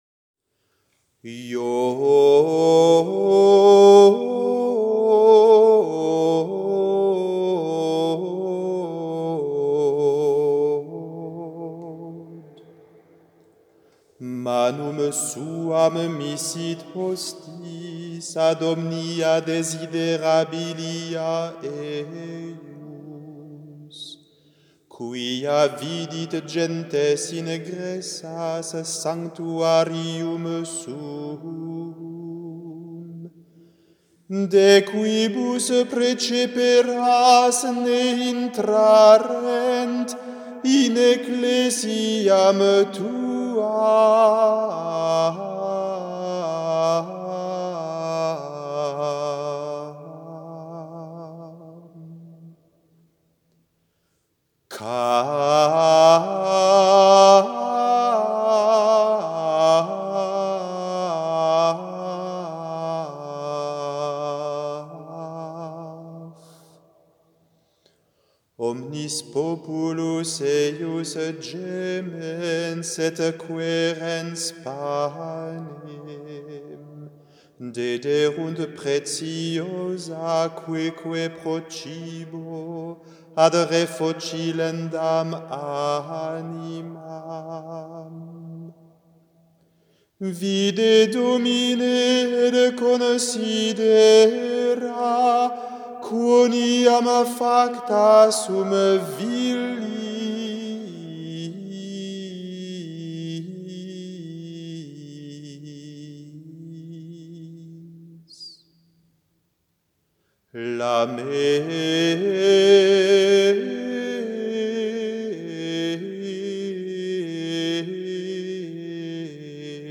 Le chant d'une lamentation (3/9)